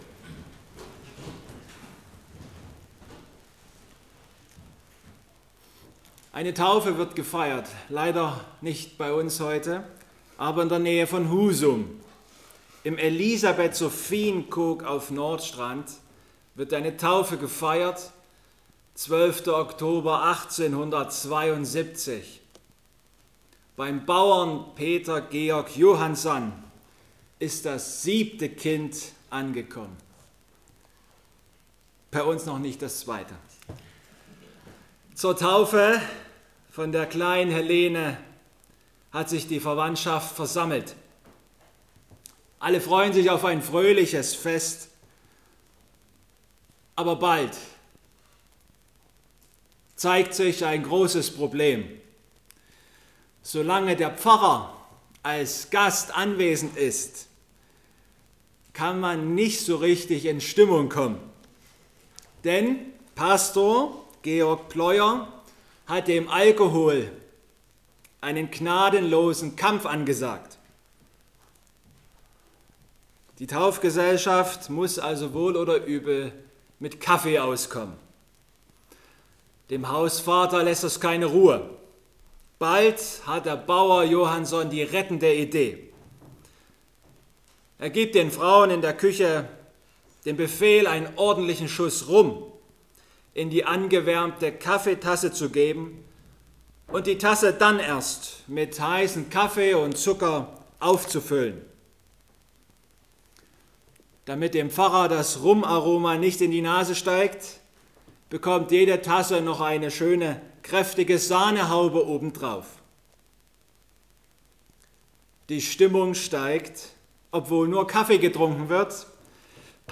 Lukas 18,9-14 Gottesdienstart: Predigtgottesdienst Obercrinitz Wir vergleichen ständig